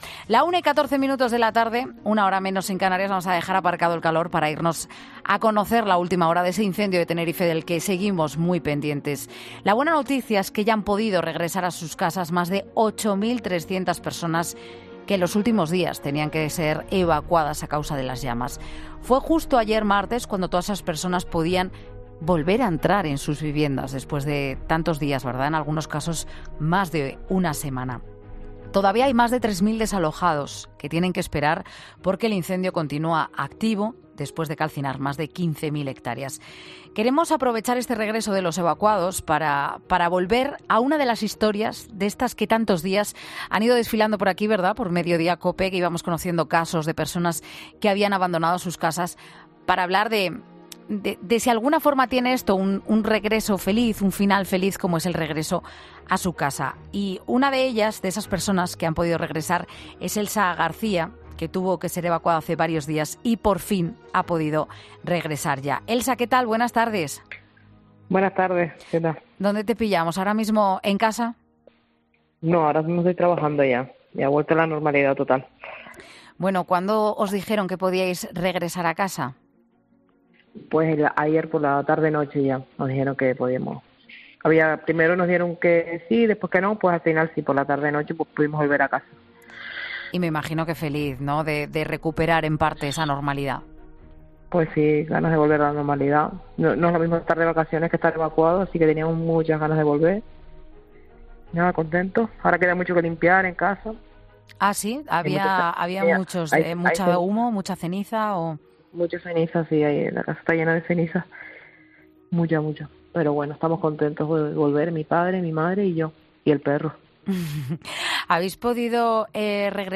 Un bombero aconseja en Mediodía COPE qué hacer para evitar incendios en nuestra casa